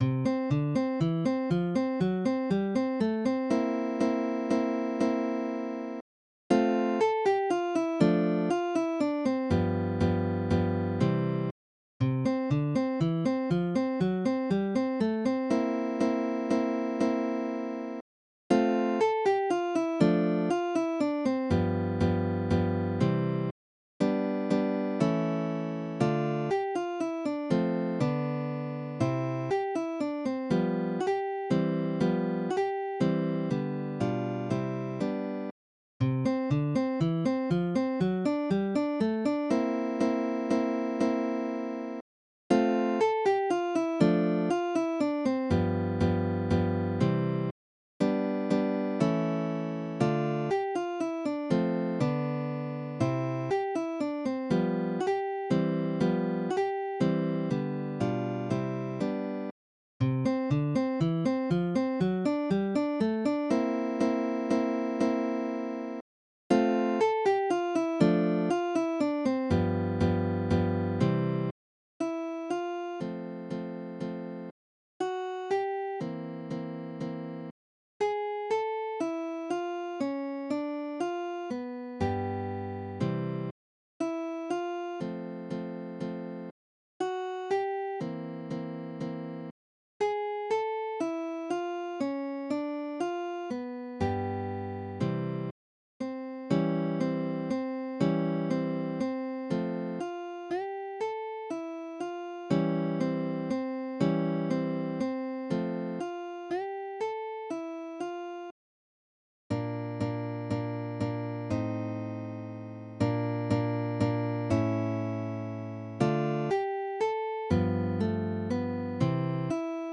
Midi音楽が聴けます 2 150円